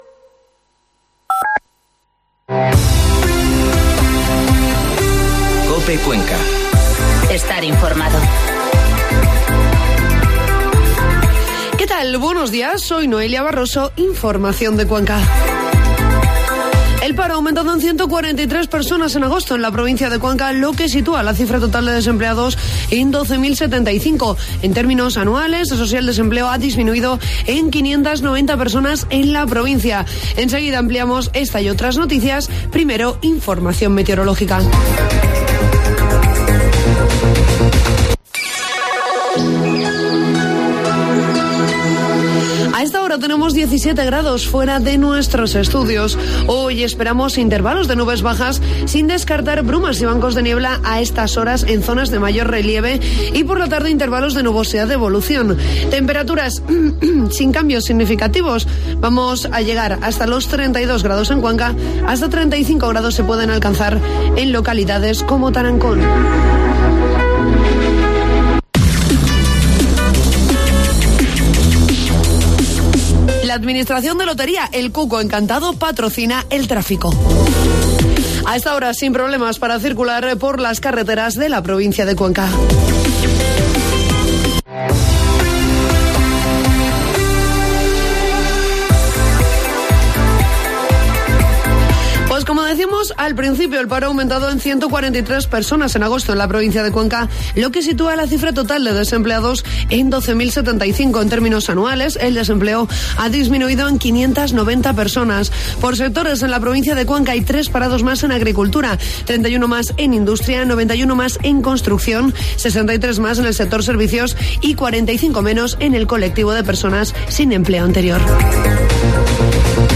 Informativo matinal COPE Cuenca 4 de septiembre